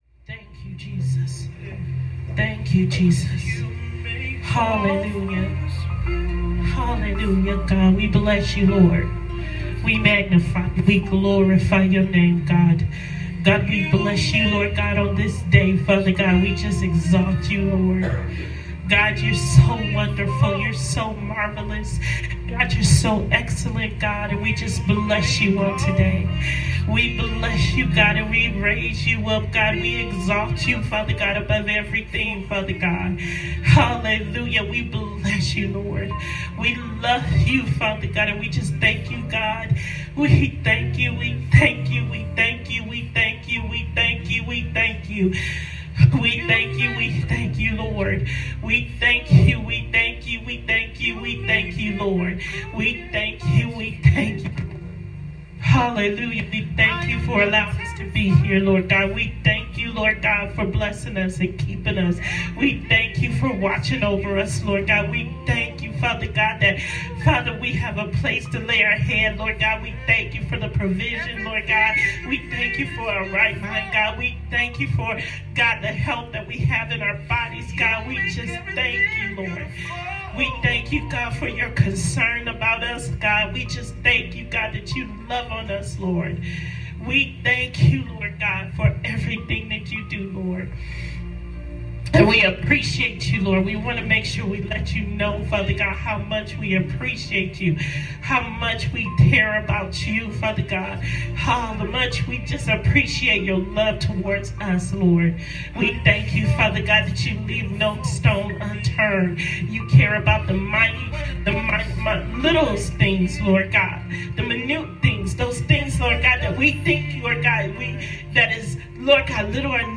Intercessory Prayer